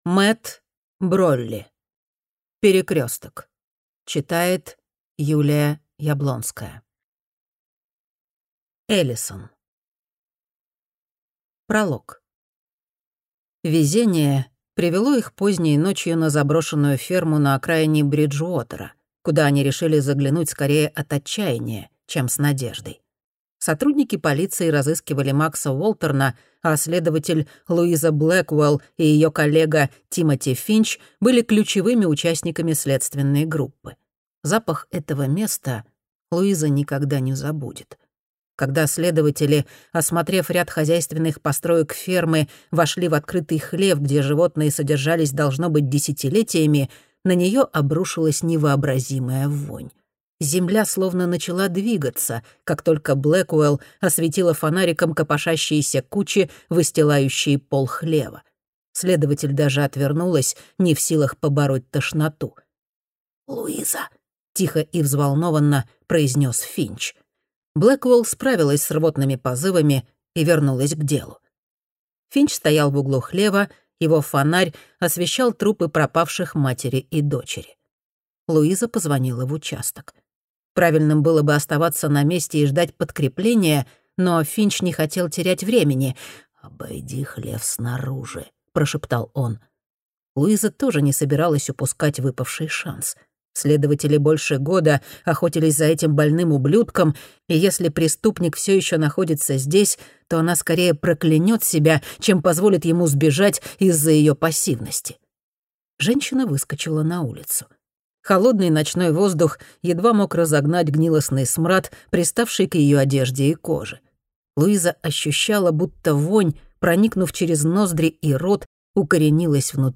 Аудиокнига Перекресток | Библиотека аудиокниг